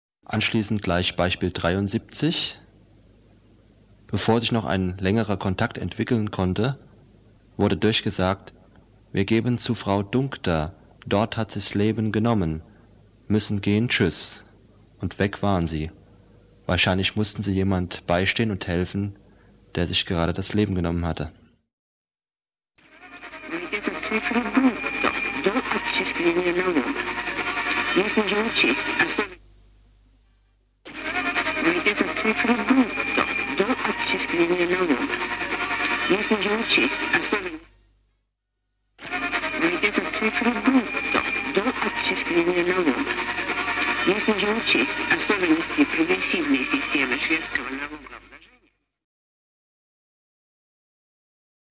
Stimmenbeispiele
Die Stimmen werden mehrfach wiedergegeben, so dass alle erstaunlichen Aussagen sehr schön verständlich sind.